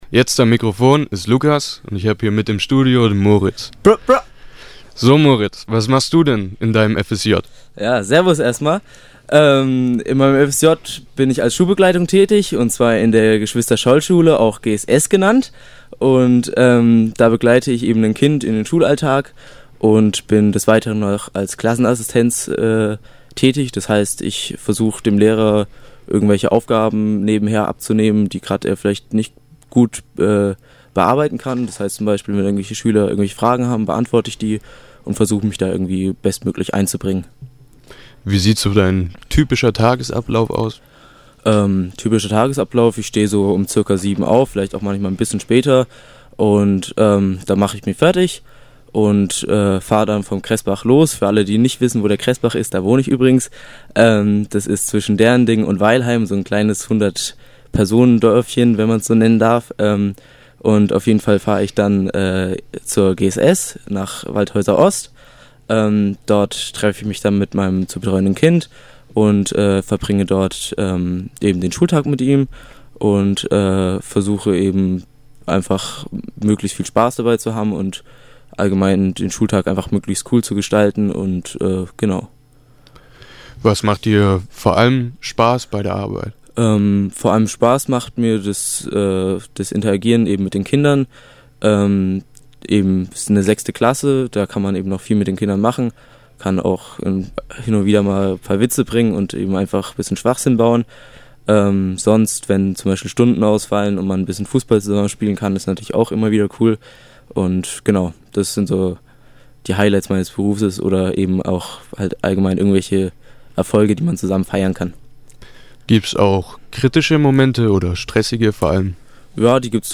Drei Tage lang stand alles im Zeichen des Radios; eindeutiger Höhepunkt waren die beiden Live-Sendungen, die die FSJler eigenständig vorbereitet und durchgeführt haben.